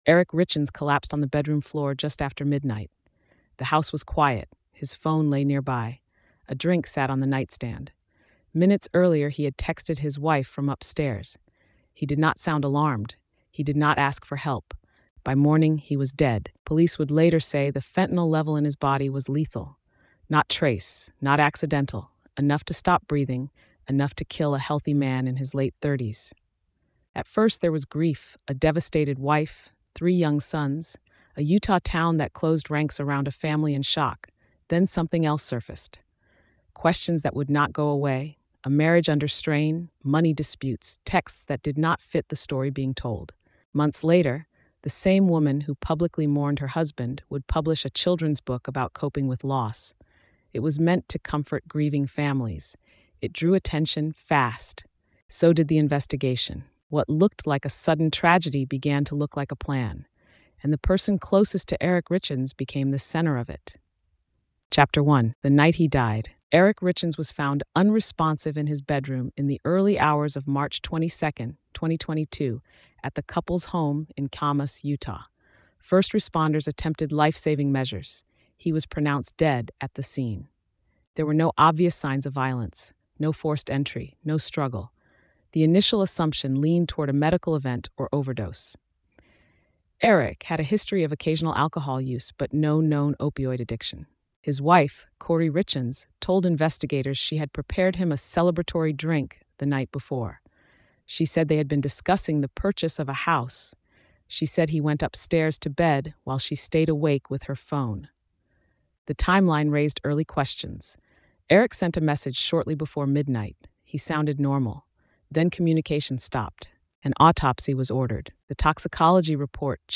Told in a forensic, grounded voice